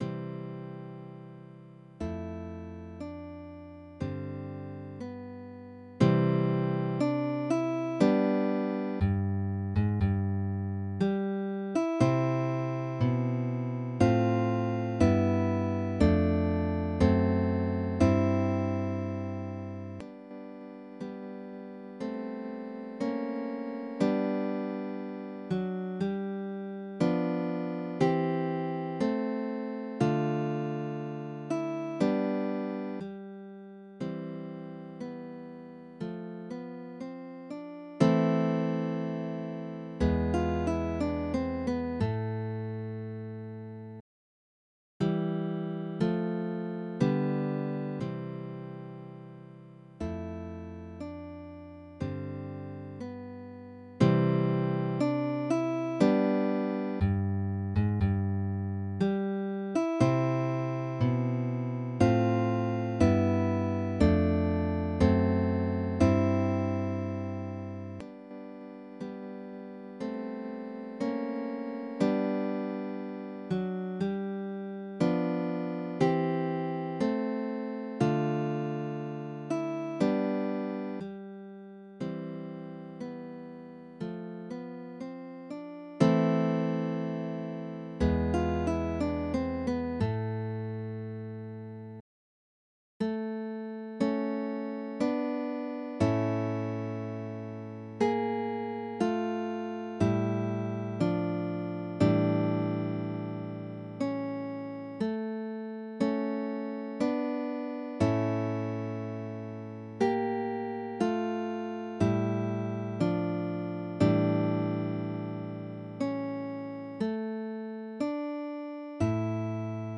Midi音楽が聴けます 2 130円